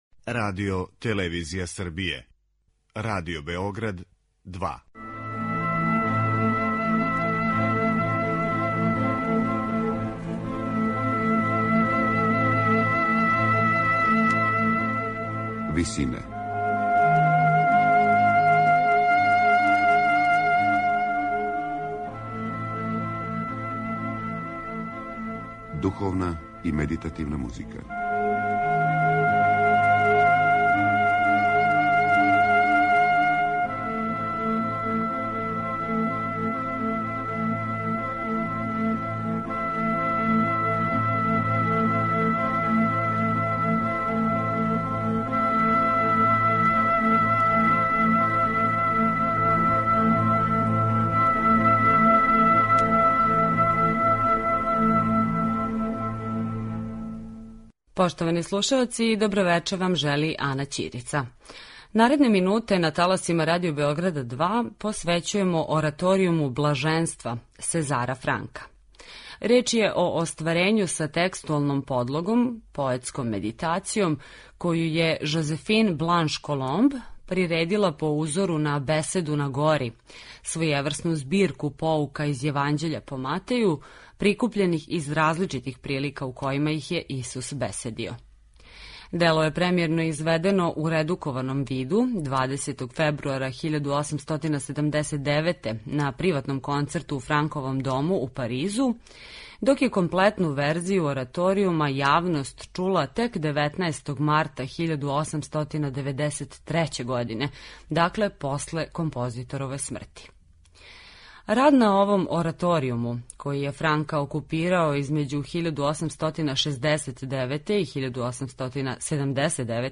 Ораторијум „Блаженства”